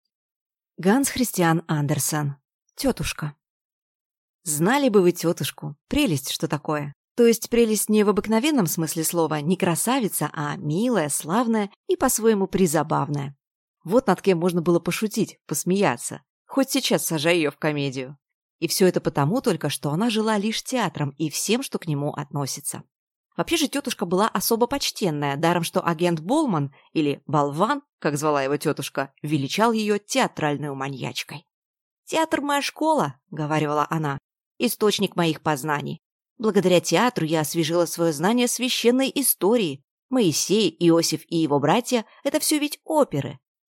Аудиокнига Тётушка | Библиотека аудиокниг